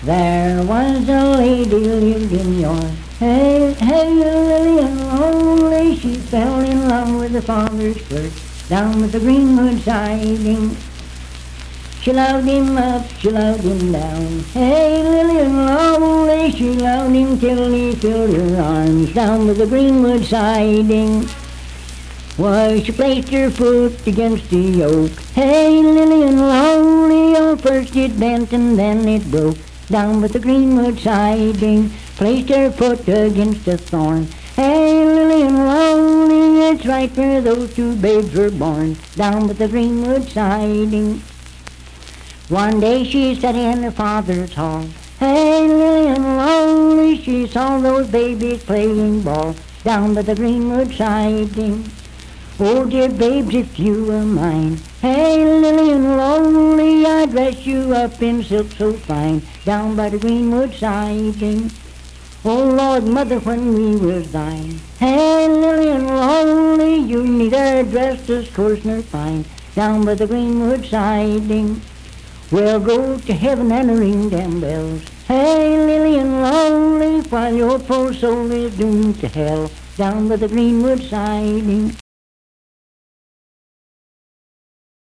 Unaccompanied vocal music
Verse-refrain 8(4w/R).
Performed in Sandyville, Jackson County, WV.
Voice (sung)